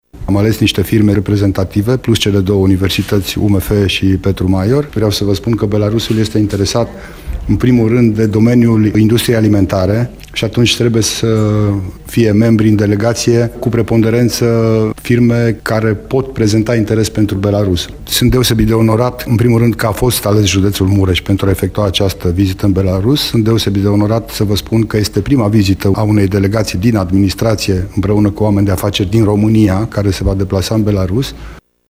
Prefectul Județului Mureș, dr. Lucian Goga: